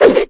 clawattack2.wav